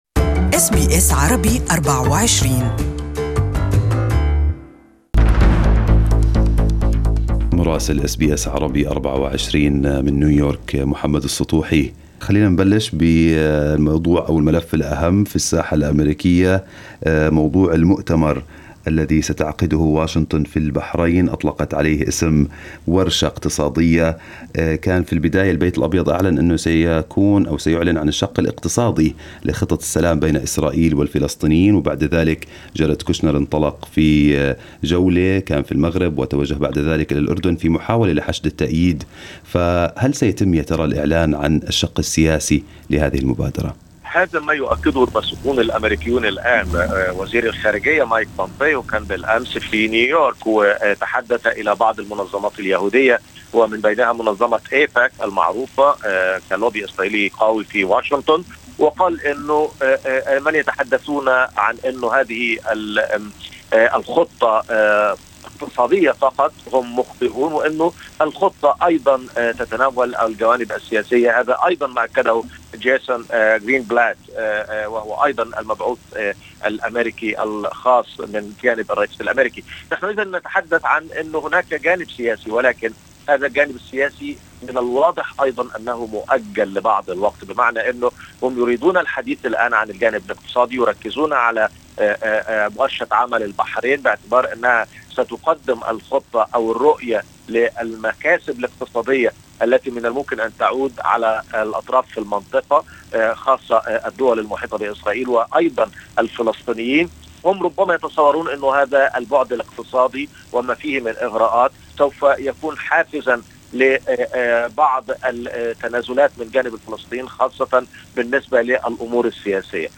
Our correspondent in Washington has the details